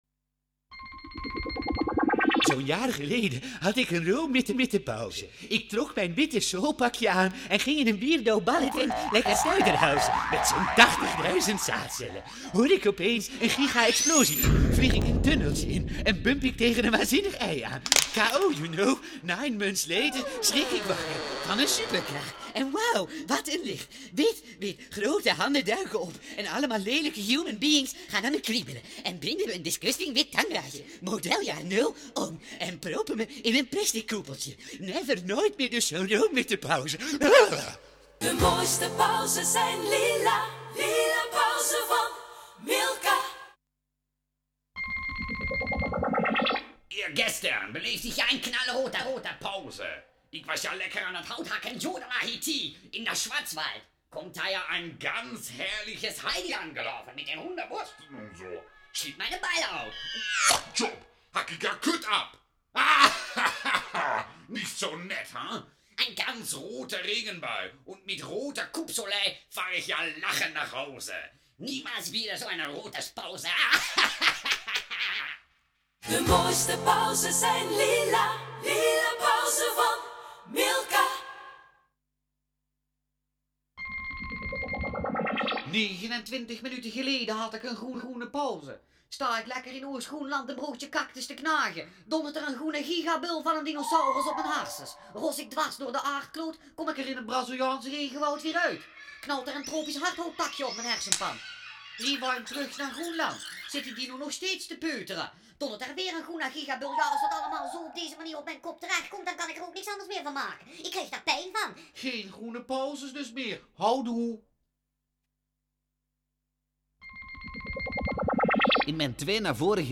Radiocommercials - karakters
Milka Lila pauze 10 x radiocommercial